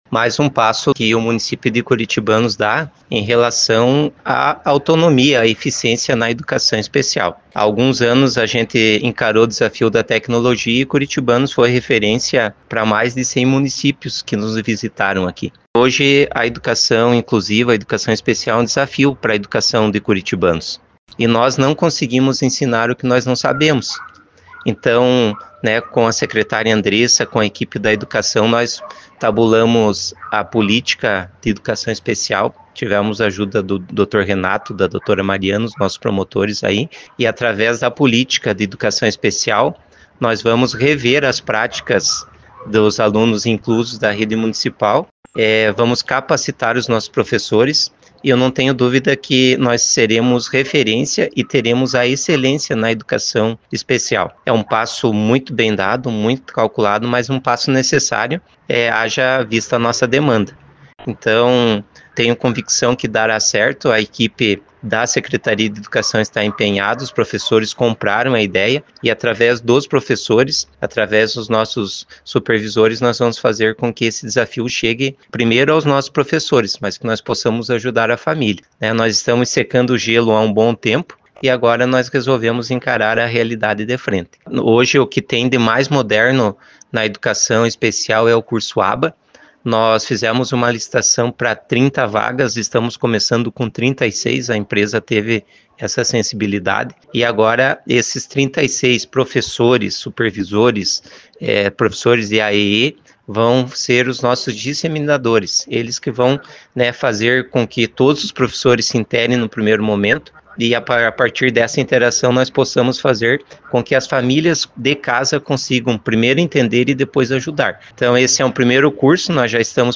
O prefeito de Curitibanos Kleberson Luciano Lima também festejou o inicio do curso e considerou o ato um momento histórico para o município.
prefeito-aba.mp3